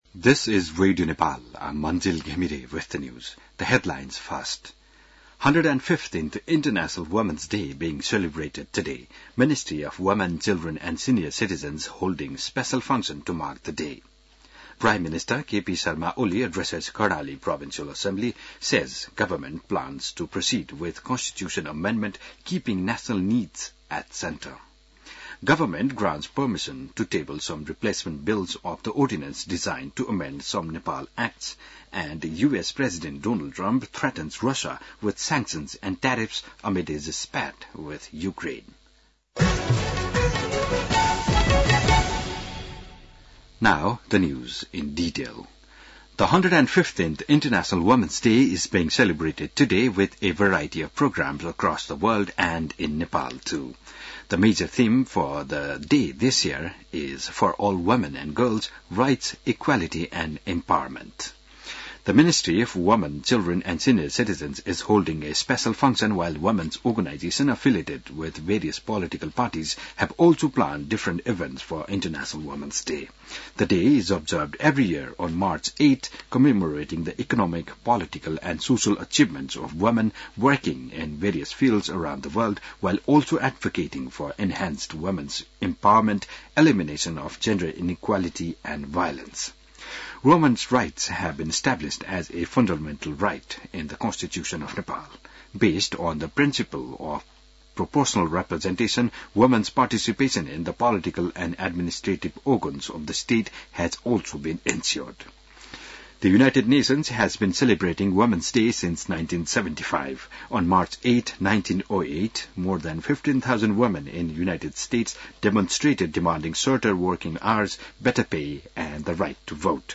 बिहान ८ बजेको अङ्ग्रेजी समाचार : २५ फागुन , २०८१